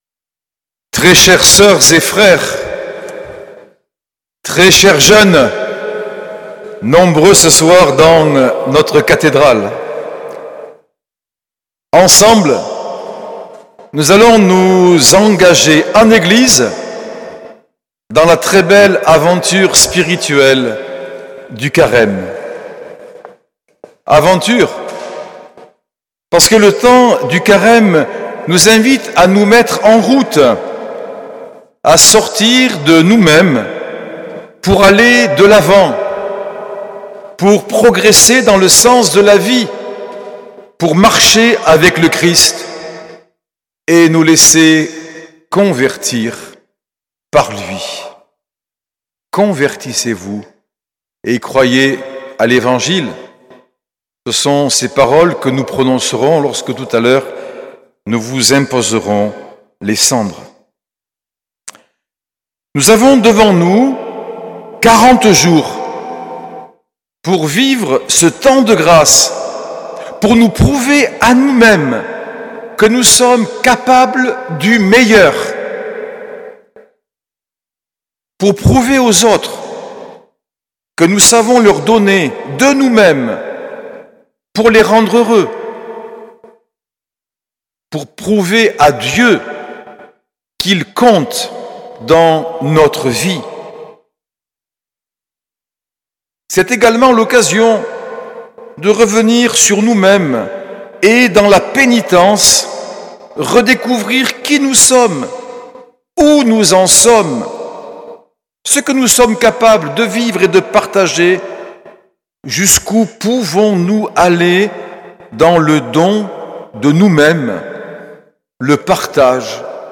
Homélie de Monseigneur Norbert TURINI, mercredi 5 mars 2025, messe de l’imposition des cendres
L’enregistrement retransmet l’homélie de Monseigneur Norbert TURINI, suivi de ses remerciements à l’assemblée, notamment pour la forte participation des catéchumènes et des jeunes.